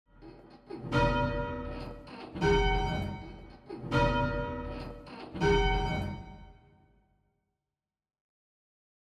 Minor 6th Intervals
The Minor Sixth interval is the inverse to a major third.